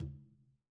Tumba-HitN_v2_rr2_Sum.wav